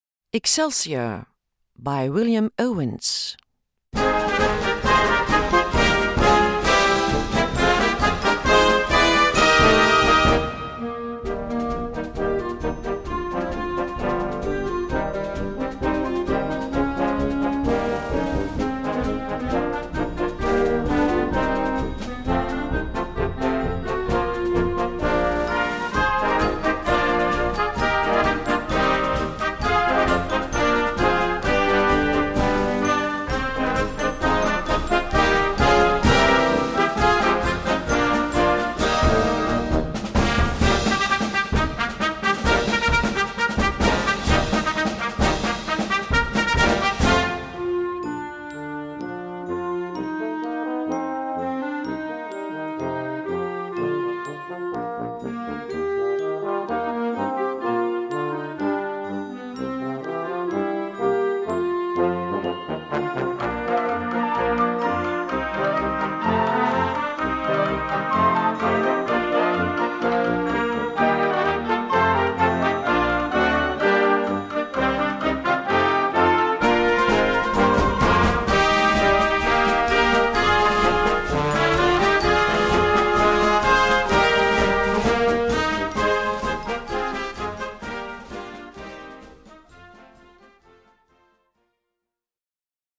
Gattung: Konzertmarsch
2:00 Minuten Besetzung: Blasorchester PDF